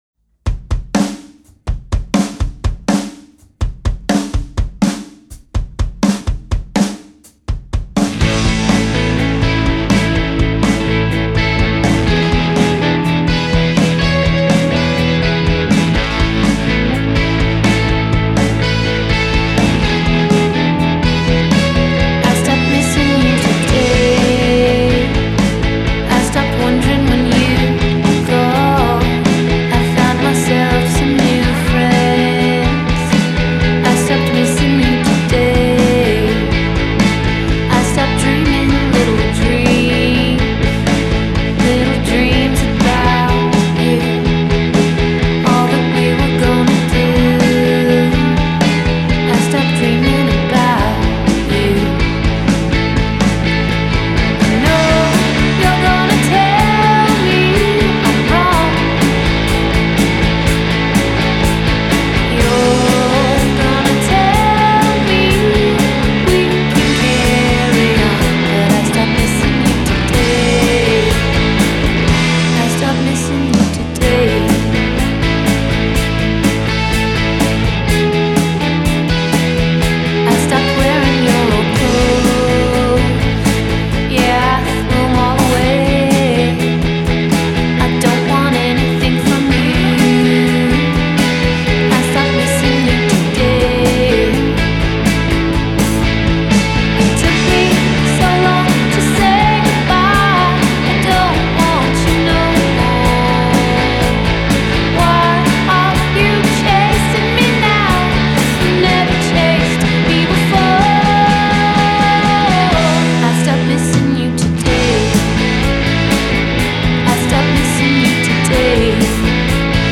an all-girl group